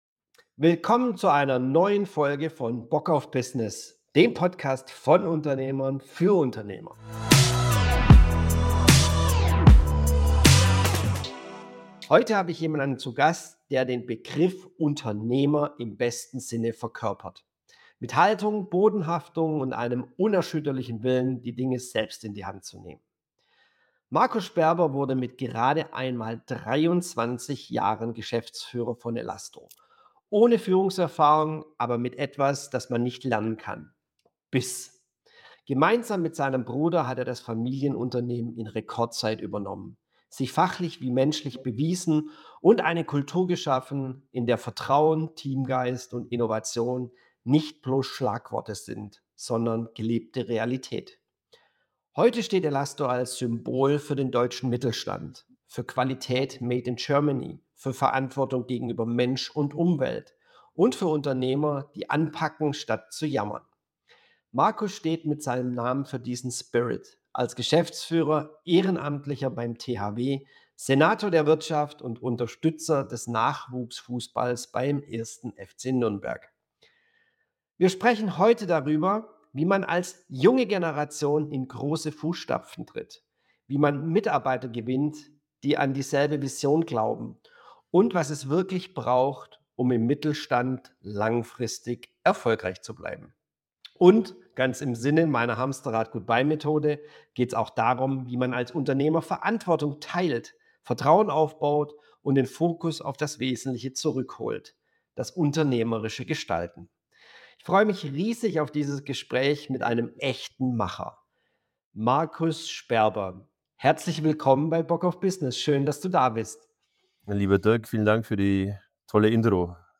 Ein Gespräch, das Mut macht.